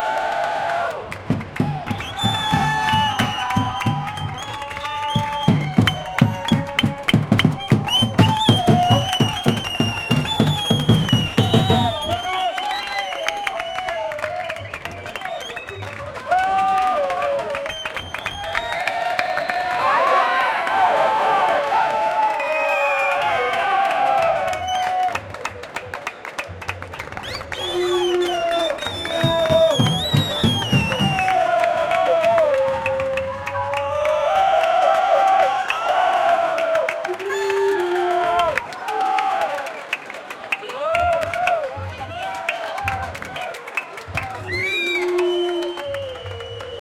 Stadium, Ambient, Hooligans Fans Clapping, Chanting, Hooligans Playing Kickdrums, Timbales, Bongoes, Whistles, Surdo, Cuica, Pandeiro, Tamborim, Congas, Tambourines, Trumpets and hats, Batucada, booing and whistling, 2004 0:47 Created Dec 10, 2024 12:13 AM
stadium-ambient-hooligans-eyudprsf.wav